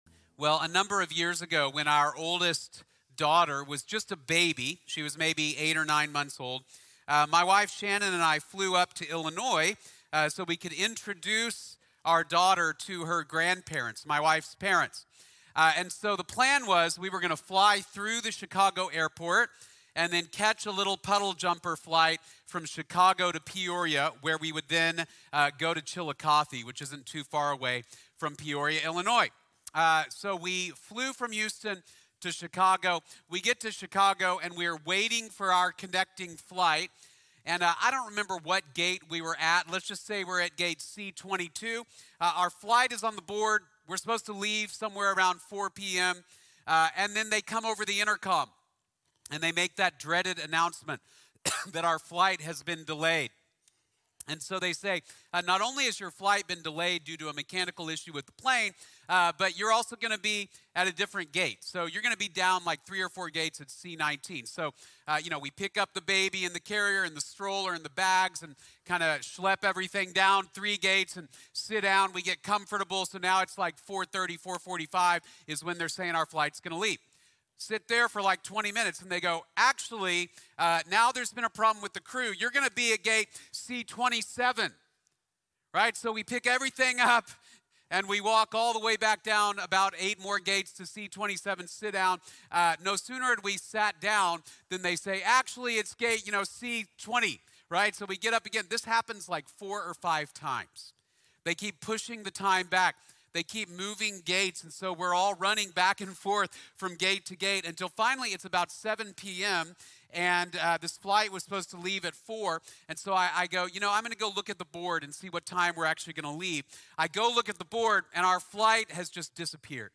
A Reason for Praise | Sermon | Grace Bible Church